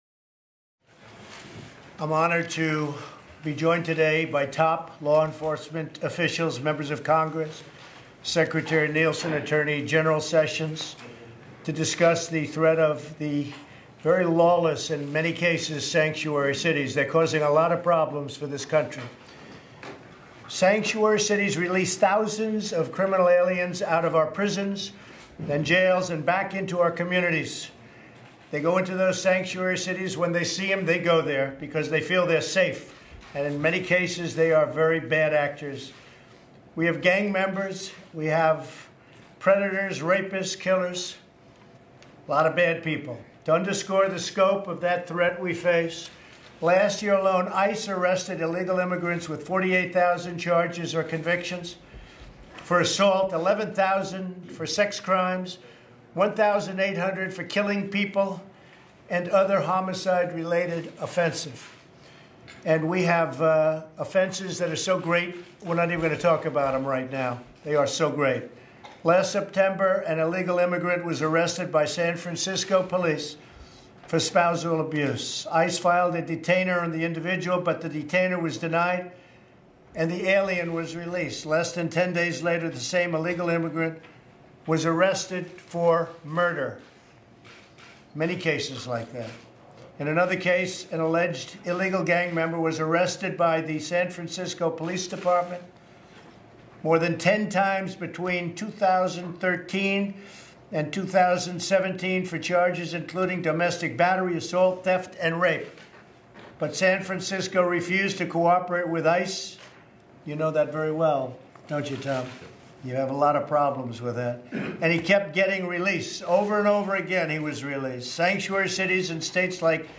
President Trump remarks on border security and immigration enforcement. Trump talks about his administration's efforts to fight "sanctuary cities" that shelter illegal immigrants and do not use local resources to enforce federal immigration laws.